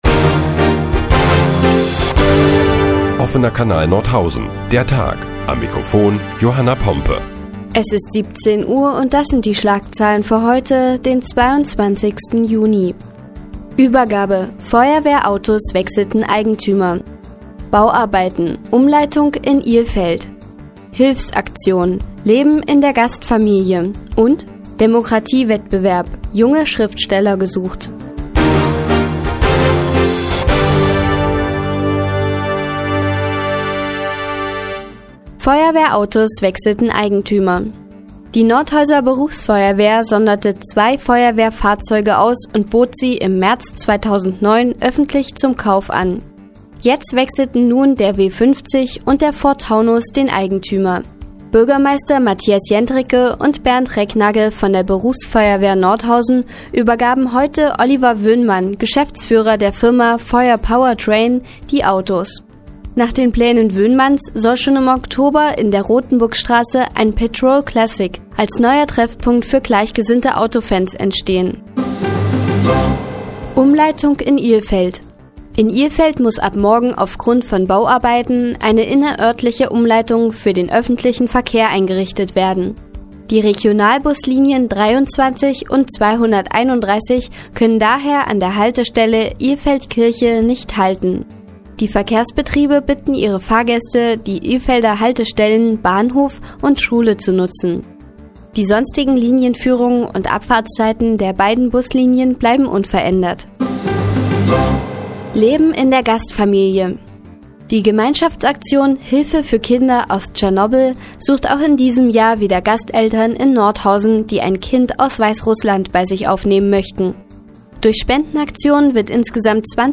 Die tägliche Nachrichtensendung des OKN ist nun auch in der nnz zu hören. Heute geht es unter anderem um die wechselnden Eigentümer von Feuerwehrautos und Leben in einer Gastfamilie.